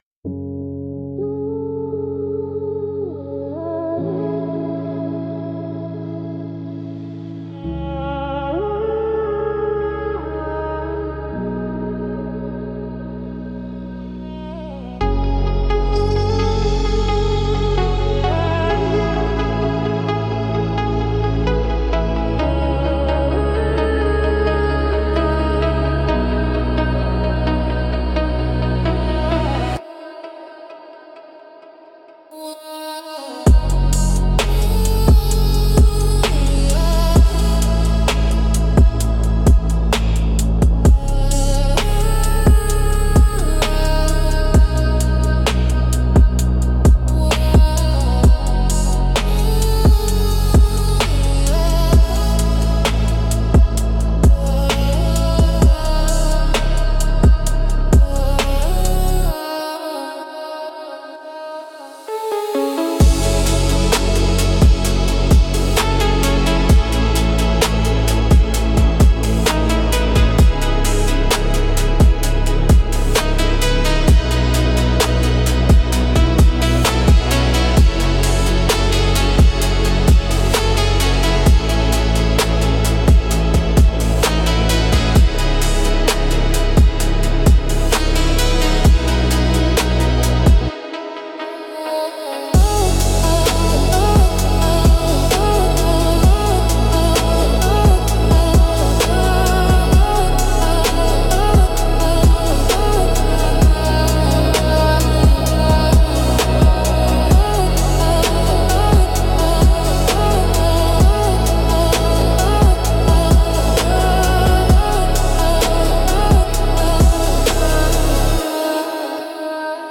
Instrumental - Circuitry of the Heart 3.32